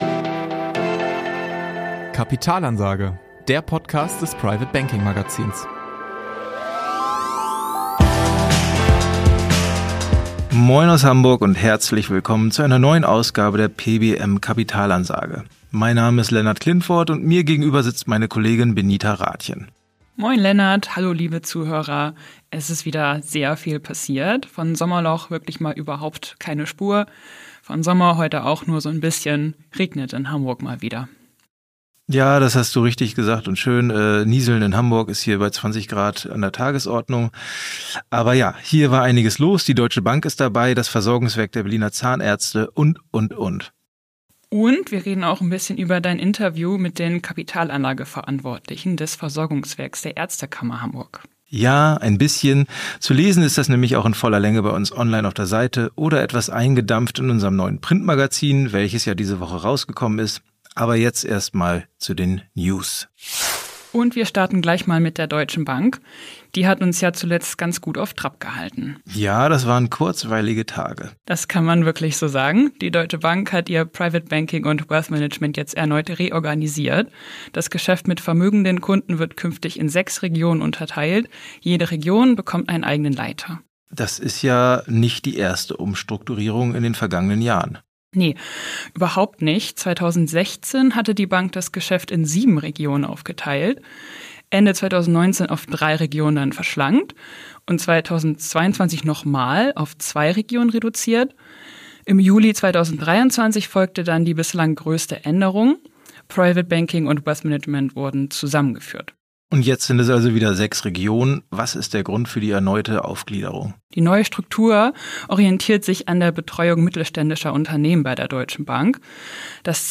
Während ein Versorgungswerk in Berlin aufräumt, öffnet das der Ärztekammer Hamburg im Interview die Türen. Außerdem berichten die Redakteure in dieser Folge über die neuen Wealth-Management-Regionen der Deutschen Bank sowie Homeoffice-Regelungen im Private Banking.